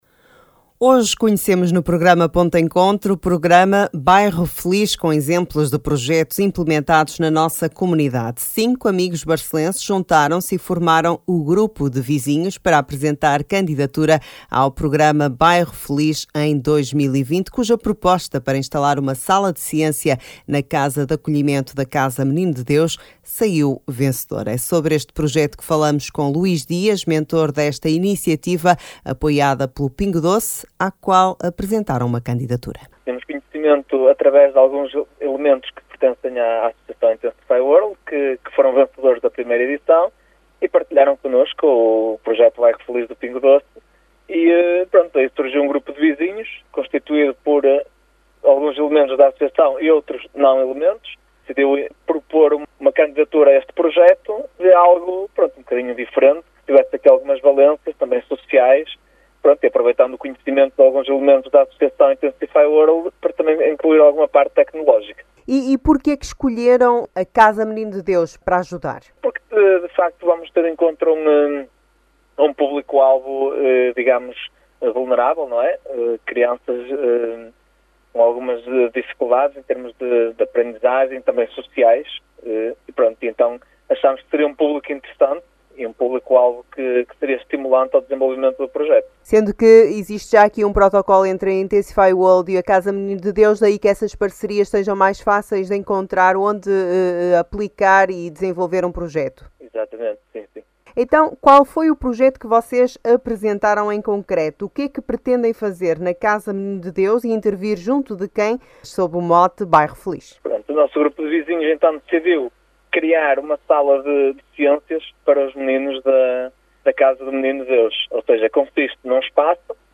Cinco amigos barcelenses juntaram-se e formaram o Grupo de Vizinhos, com apoio da Associação Intensify World, para apresentar candidatura ao Programa Bairro Feliz, cuja proposta para instalar uma sala de ciência na Casa de Acolhimento do Menino de Deus, saiu vencedora. Isso mesmo conta à Rádio Barcelos